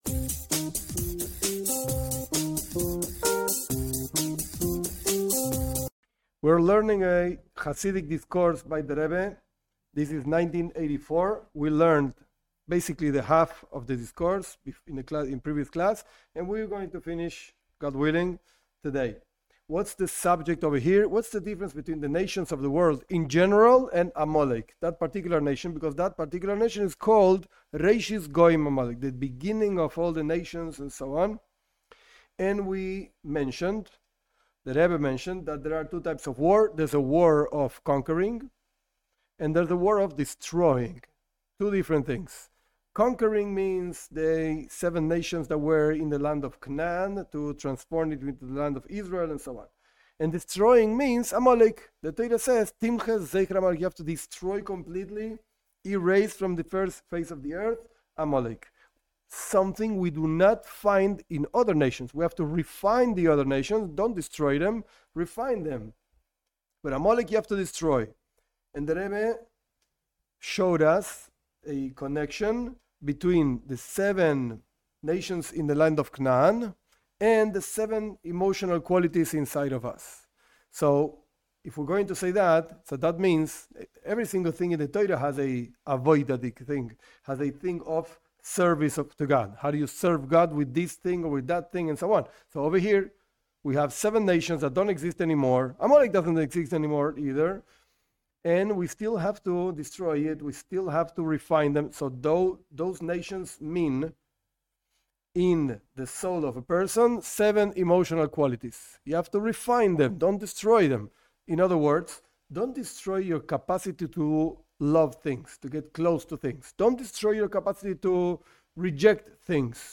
This is a chassidic discourse by the Rebbe, Rabi Menachem Mendel Schneerson, of the year 1984. In this discourse the Rebbe explains the spiritual meaning of the war against the nations of the Land of Cnaan and against Amalek, the difference between them and their lessons.